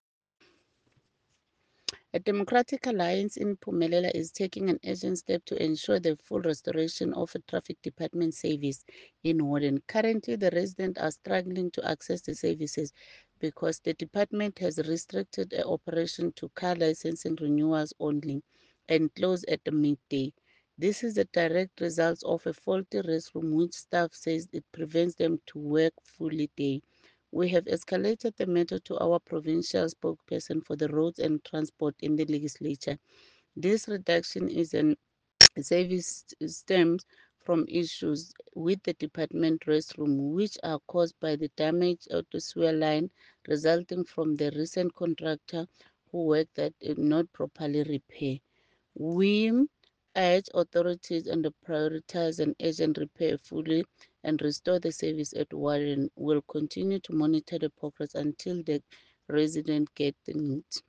English soundbite by Cllr Ntombi Mokoena and